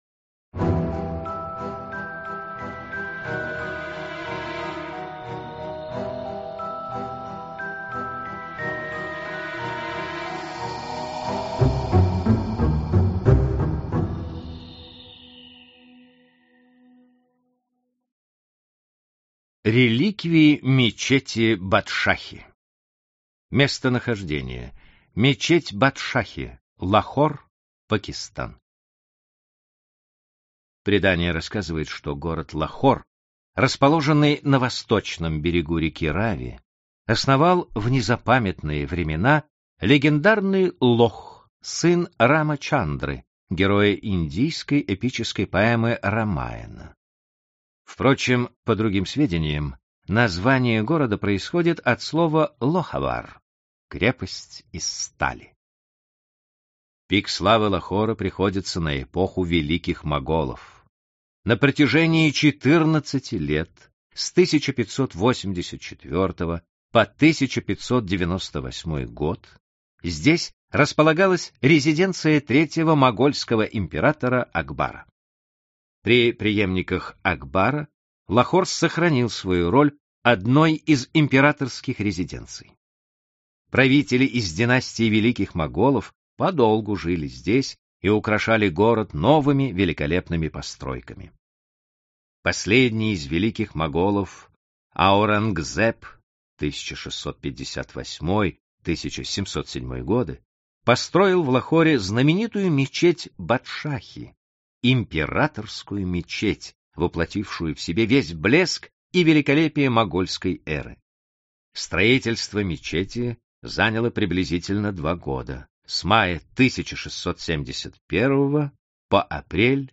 Аудиокнига Великие реликвии мира | Библиотека аудиокниг